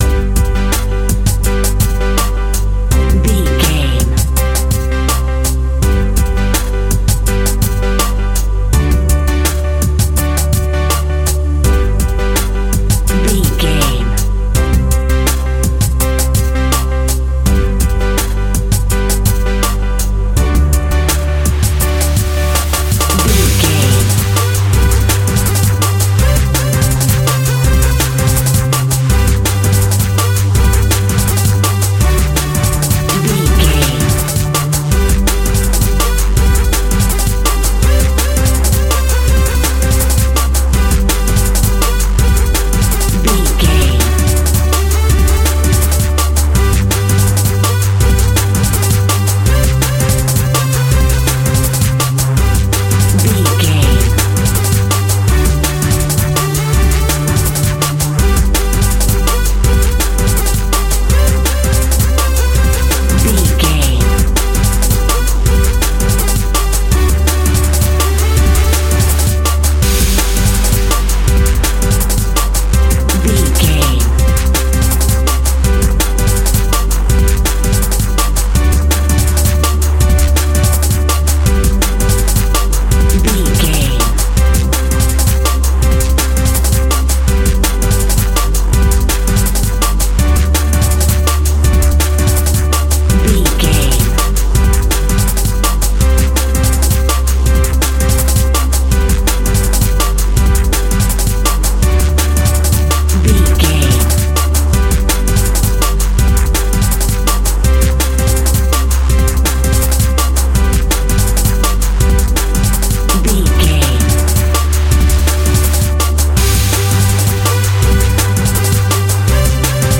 Ionian/Major
Fast
groovy
uplifting
futuristic
driving
energetic
bouncy
synthesiser
drum machine
electric piano
electronic
instrumentals
synth bass
synth lead
synth pad